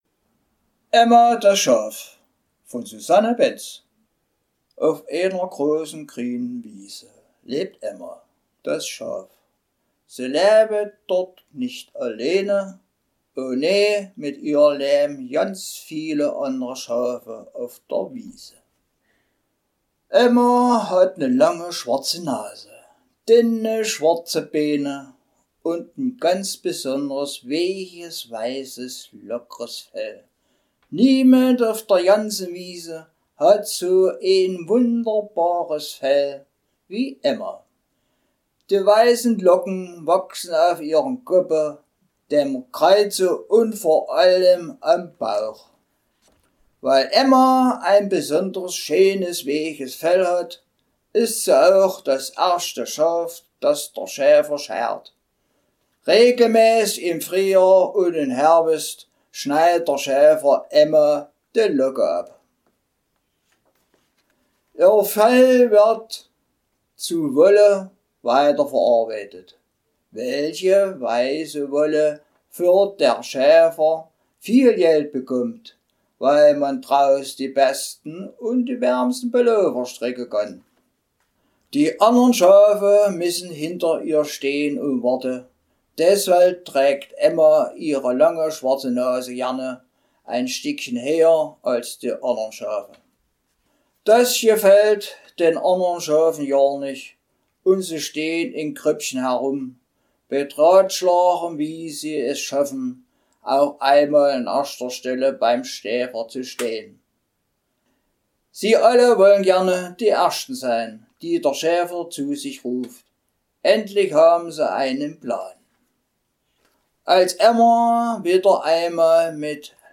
Das Hörstudio für deutsche Dialekte » Sachsen - Anhalt - Emma, das Schaf
Emma, das Schaf - Dialekt Sachsen - Anhalt